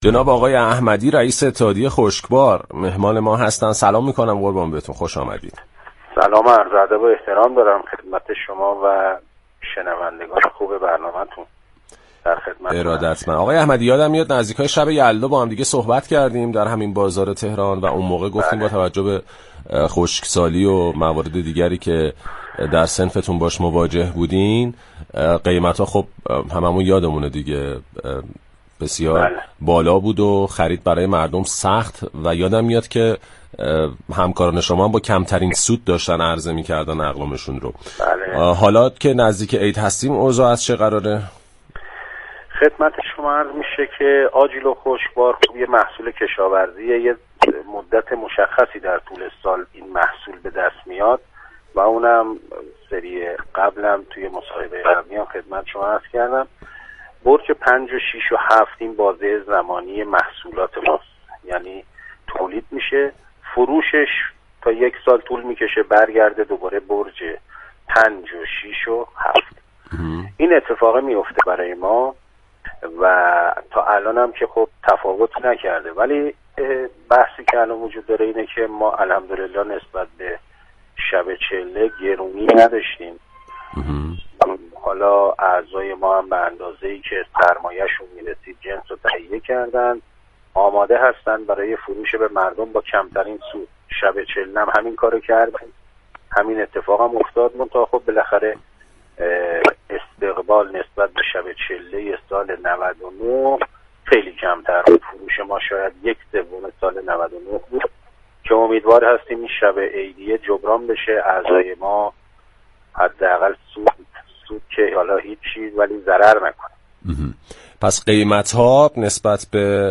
در گفتگو با بازار تهران رادیو تهران